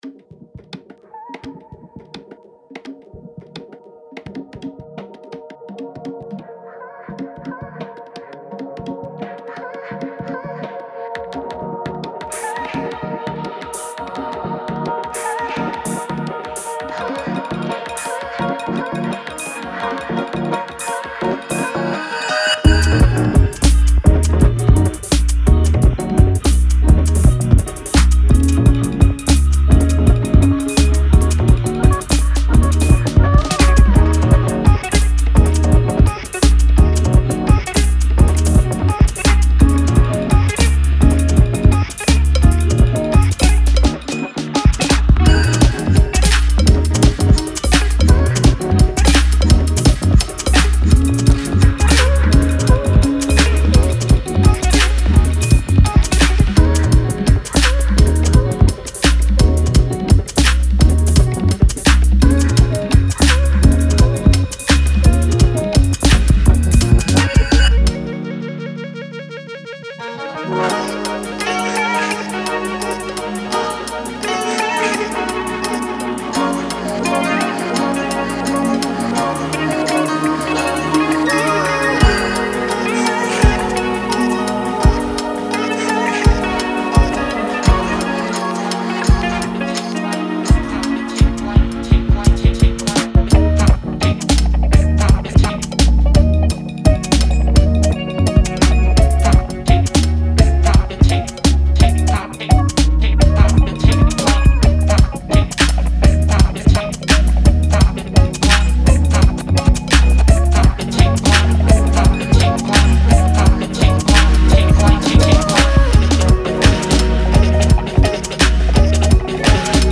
A quick & dirty hyperdub remix